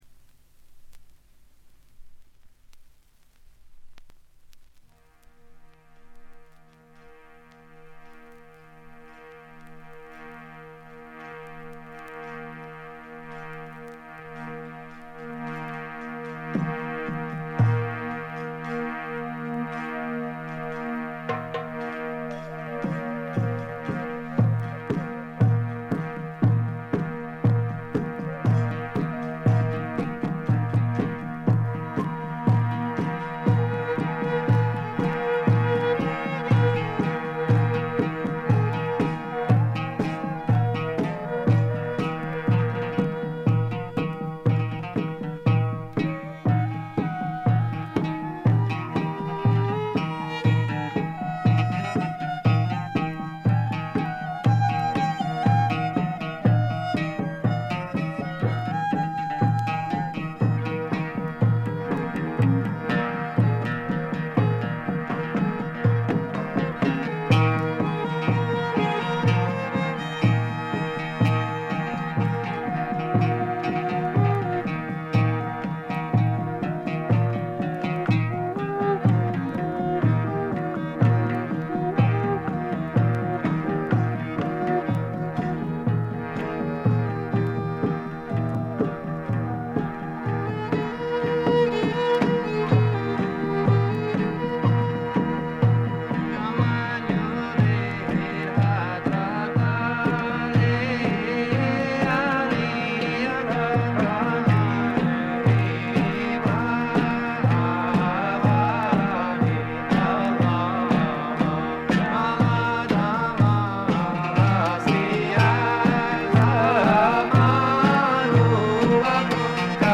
静音部で軽微なバックグラウンドノイズ、チリプチ。
試聴曲は現品からの取り込み音源です。
Drums
Mandola
Vocals, Ney
Viola